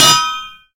anvil_land.ogg